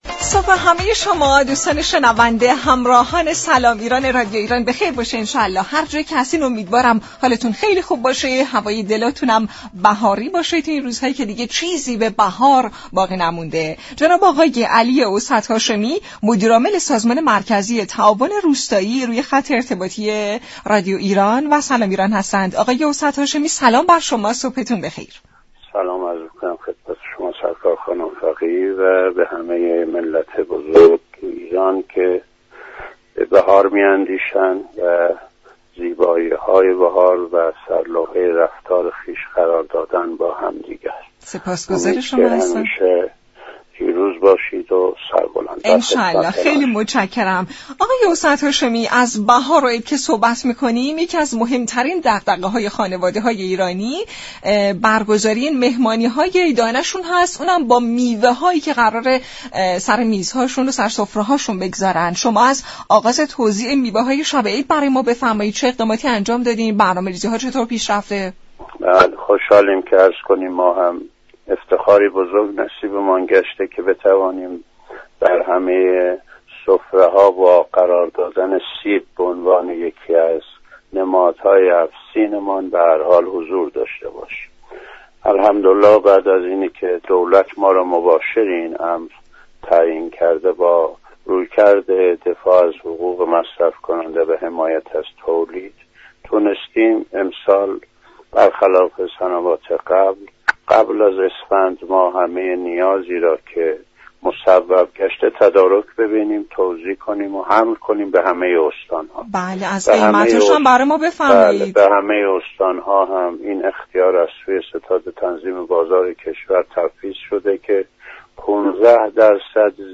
مدیر عامل سازمان مركزی تعاون روستایی در گفت و گو با برنامه «سلام ایران» گفت: دولت بدون در نظر گرفتن قیمت خریداری شده محصول، تمامی اقلام را با كیفیت مطلوب و به عنوان عیدانه در اختیار مردم قرار می دهد.